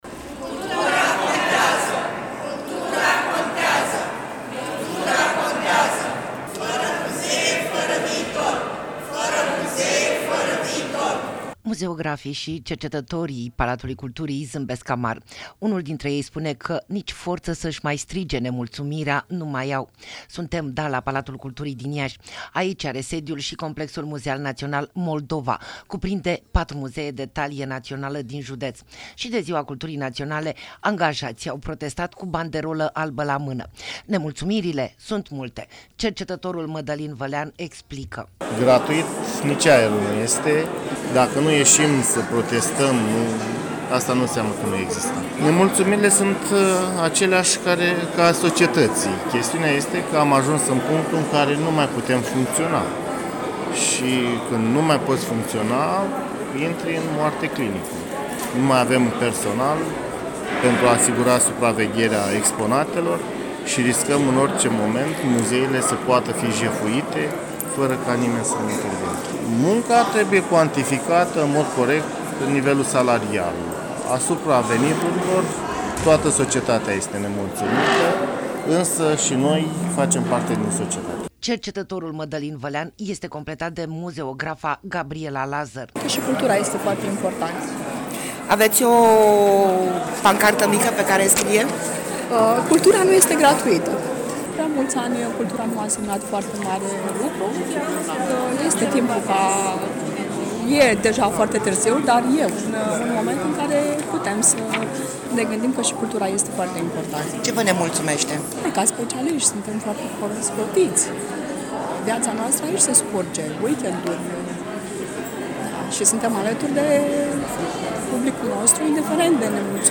Protest-Palatul-Culturii-Iasi.mp3